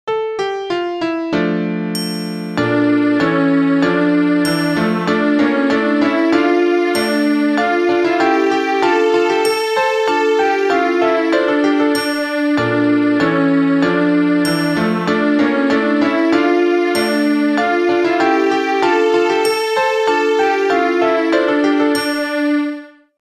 two part round